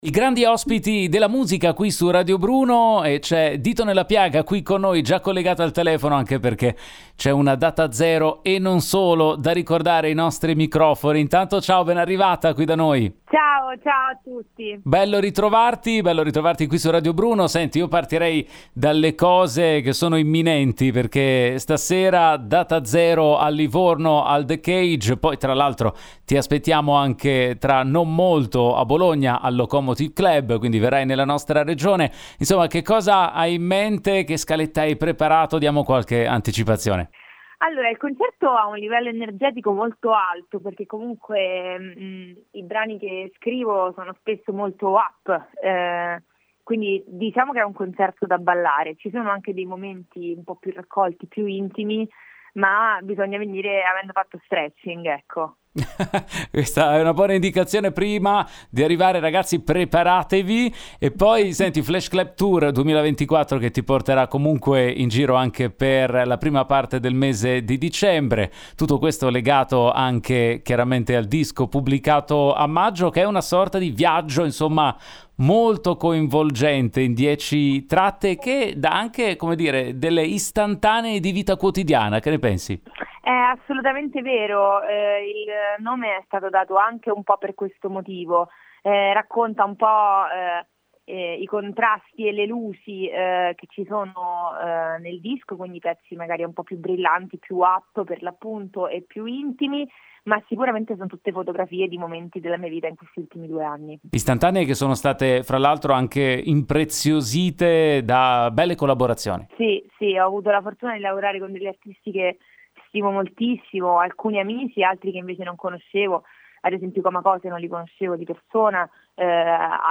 Home Magazine Interviste Ditonellapiaga presenta il “Flash Club Tour 2024”